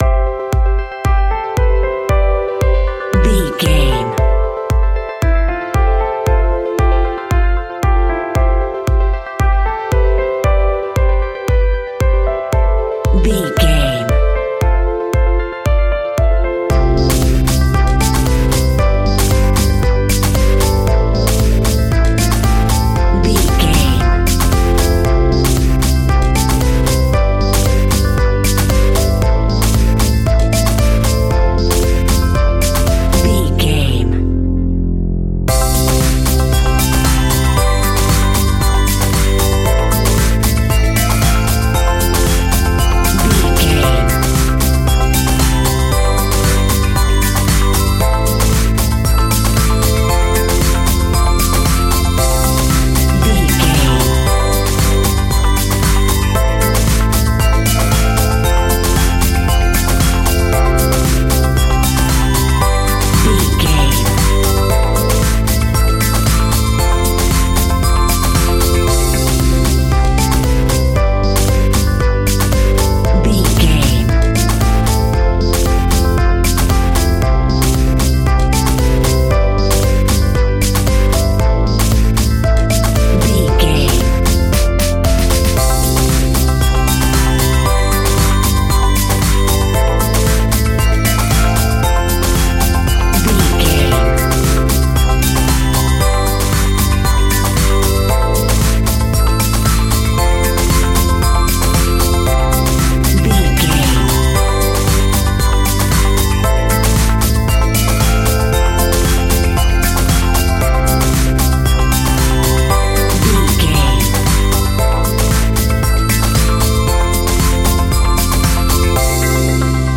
Ionian/Major
groovy
uplifting
futuristic
energetic
happy
bouncy
piano
synthesiser
drum machine
electronica
synth leads
synth bass
synth pad
robotic